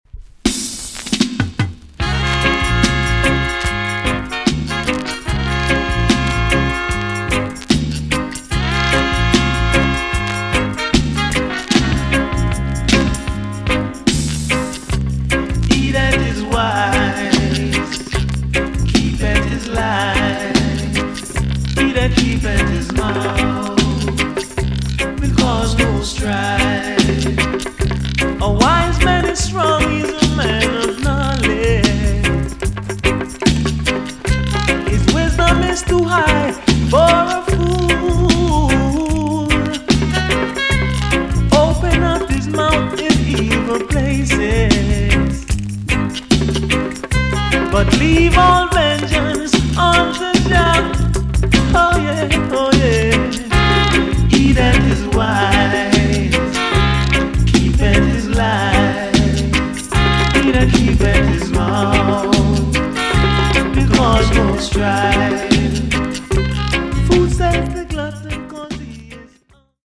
TOP >DISCO45 >80'S 90'S DANCEHALL
EX-~VG+ 少し軽いチリノイズが入りますが良好です。
RARE , NICE EARLY 80'S DJ TUNE!!